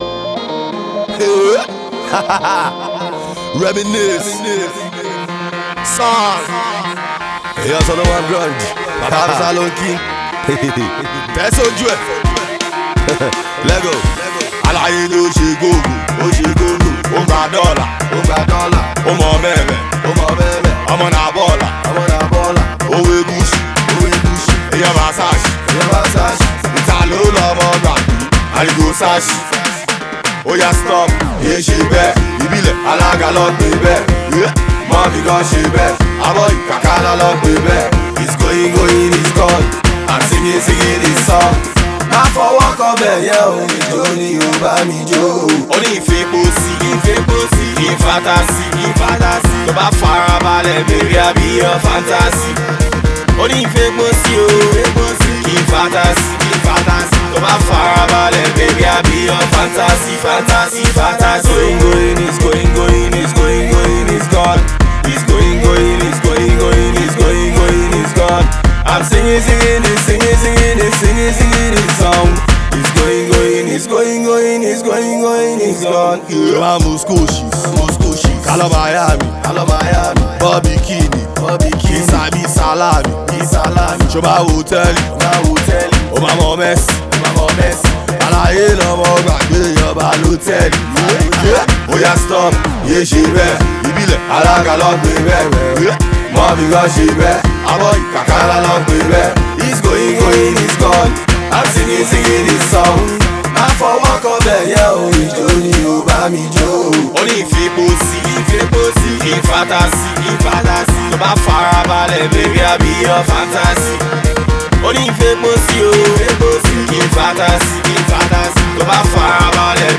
He calls this fun new joint